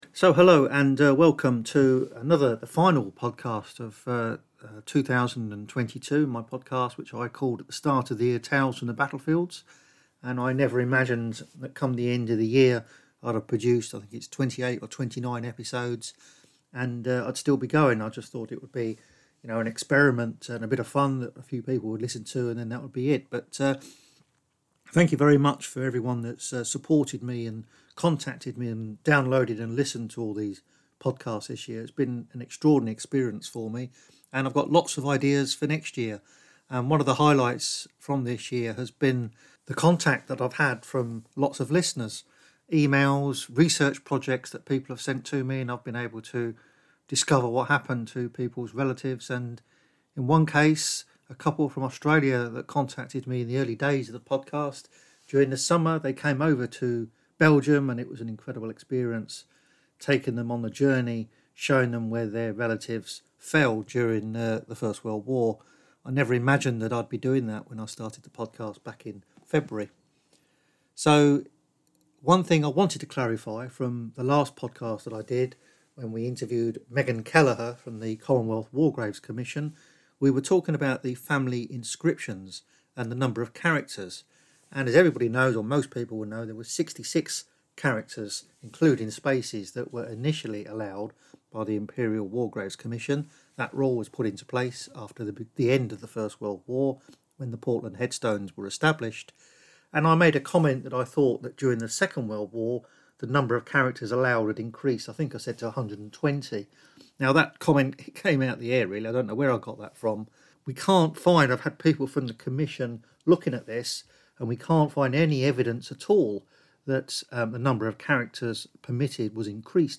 In this episode we are guided around Tyne Cot Cemetery by a Belgian guide who lives on the doorstep of the largest CWGC Cemetery in the world.